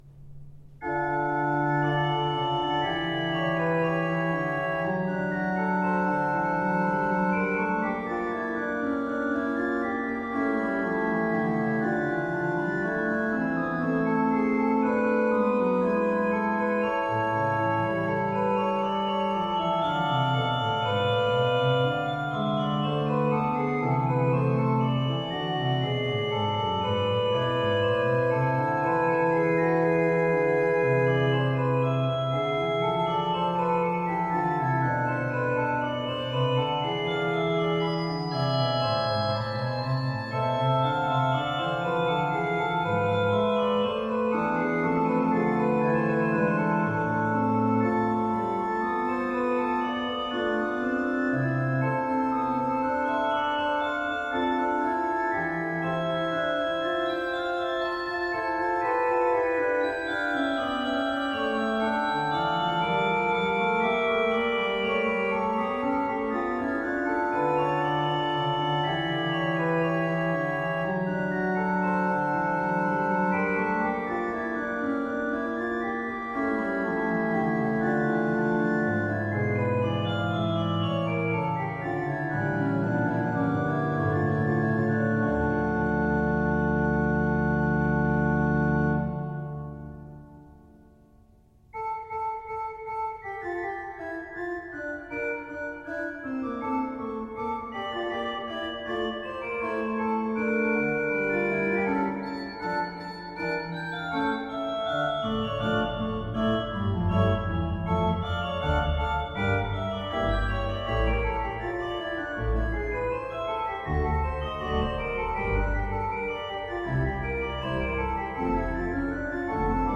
Classical Bach, Johann Sebastian Prelude and Fugue in D Minor--BWV 539 Organ version
Organ Classical Organ Classical Organ Free Sheet Music Prelude and Fugue in D Minor--BWV 539
Free Sheet music for Organ